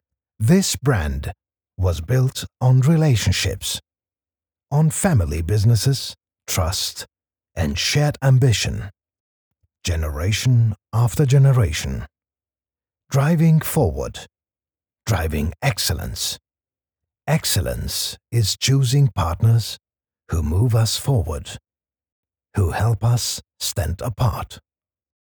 Male
Adult (30-50)
Corporate